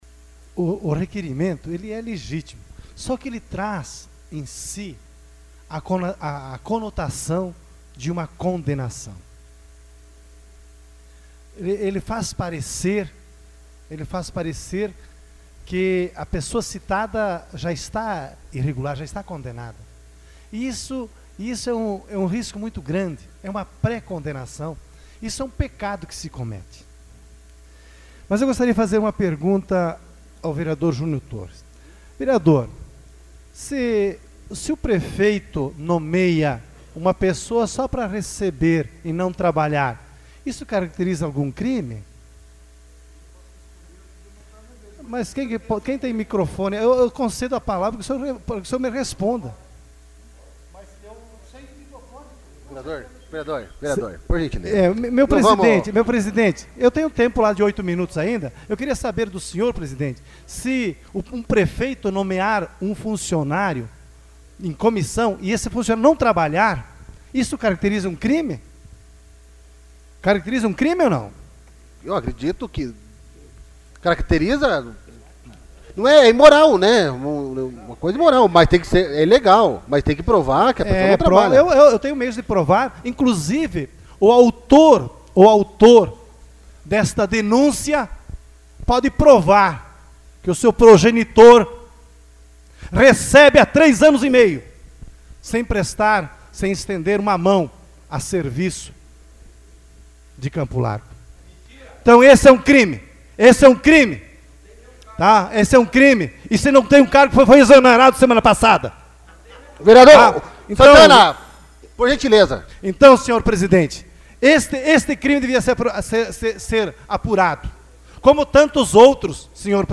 Discussão AVULSO 12/07/2016 Luiz Rossatto